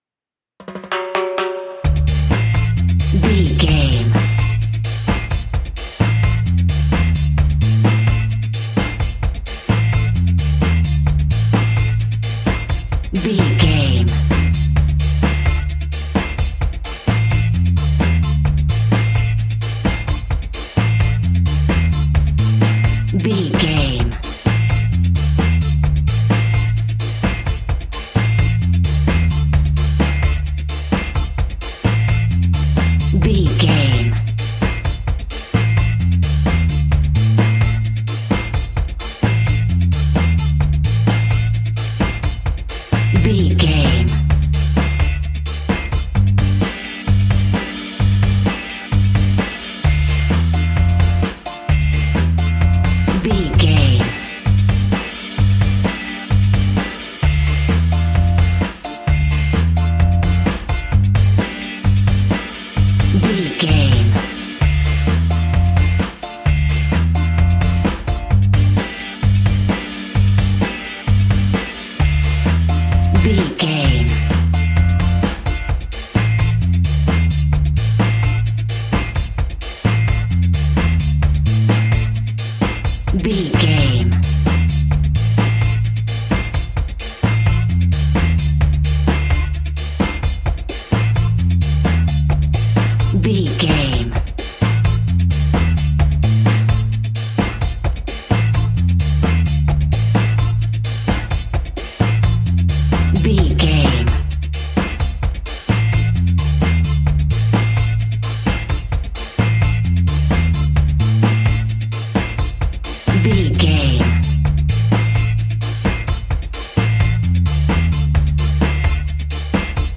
Classic reggae music with that skank bounce reggae feeling.
Aeolian/Minor
dub
reggae instrumentals
laid back
chilled
off beat
drums
skank guitar
hammond organ
percussion
horns